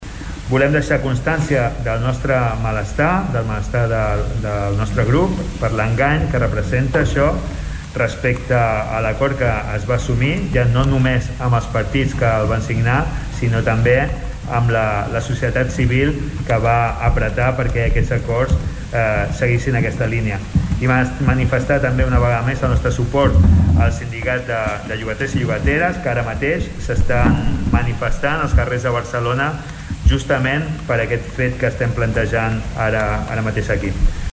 El regidor d’En Comú Podem Tordera, Salvador Giralt lamentava aquest gir de guió.